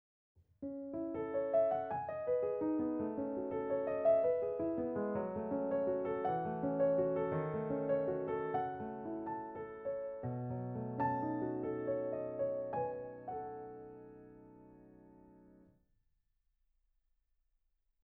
• StudioGrands：残響音が少ないためタイトな印象。
Ivory IIのStudioGrandsとGrandPianosに収録されているピアノのデフォルト的なプリセットで同じピアノフレーズを鳴らしてみました。
Bösendorfer 225
絶妙なデッドさを保ちながら心地良いルーム感があるピアノプリセットが多いです。
スタジオサイズのレコーディングで鳴っているようなタイトなピアノサウンドを鳴らしたい人に最適です。
ivoryII_bosendorfer225.mp3